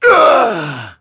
Crow-Vox_Damage_03.wav